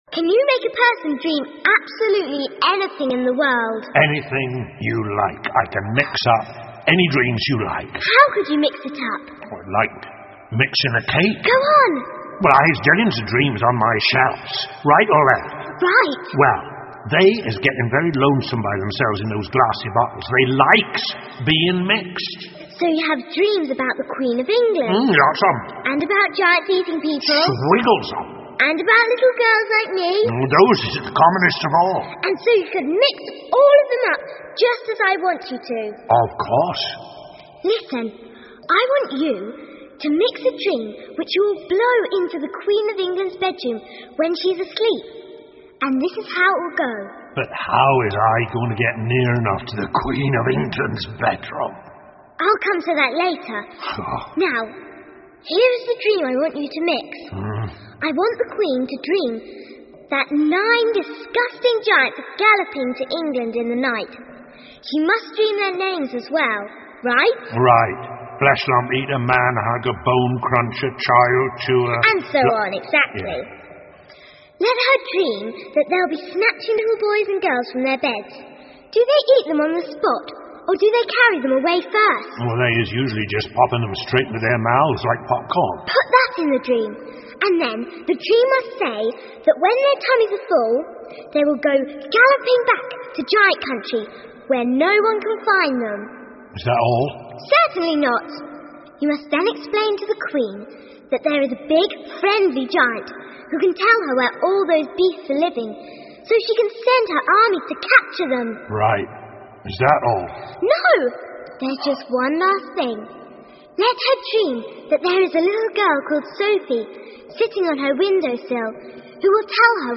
The BFG 好心眼儿巨人 儿童广播剧 11 听力文件下载—在线英语听力室